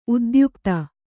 ♪ udyukta